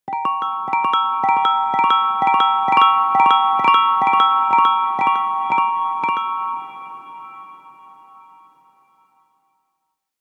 Fantasy Bell Sound Effect
Magical, whimsical melody perfect for TikTok or YouTube videos, apps, animations, or any project requiring a touch of enchantment.
Genres: Sound Logo
Fantasy-bell-sound-effect.mp3